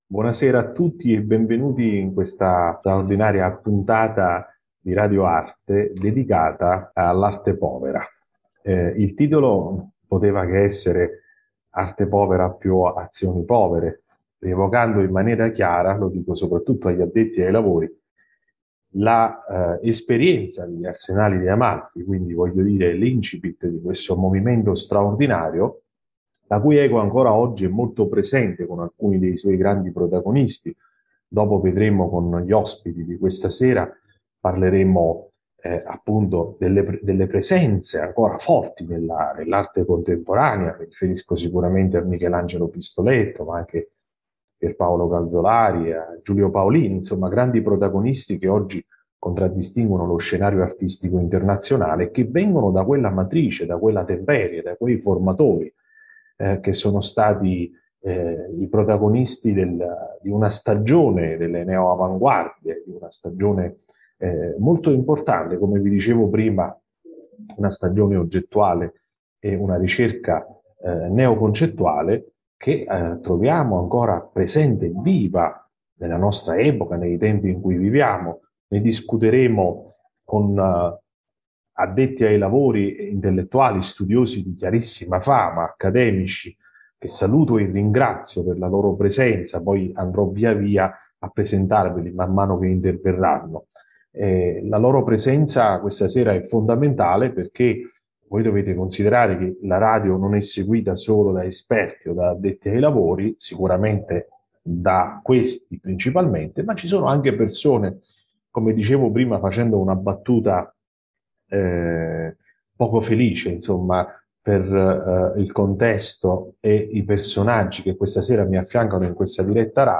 LIVE #1-2023 INCONTRO
Talk intorno alla poesia